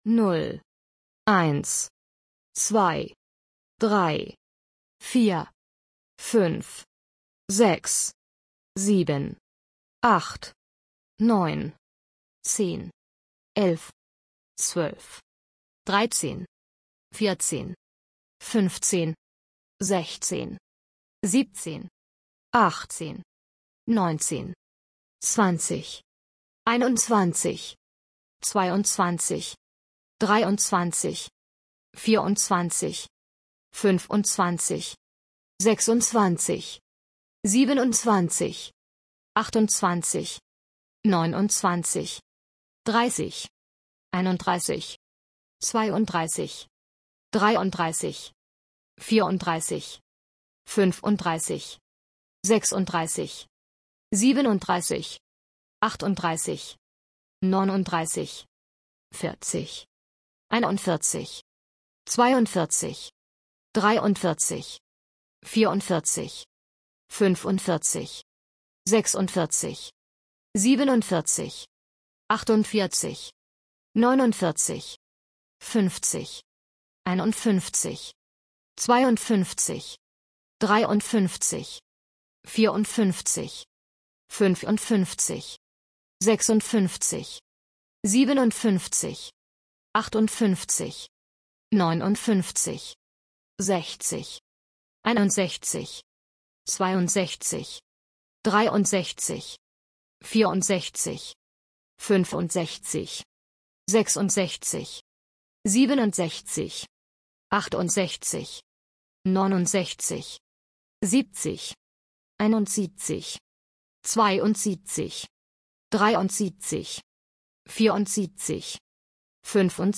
تمرین شنیداری اعداد آلمانی
تلفظ اعداد آلمانی (0 تا 10)
1Einsآینس
2Zweiتسوای
21einundzwanzigآین‌اوند‌تسوان‌تسیگ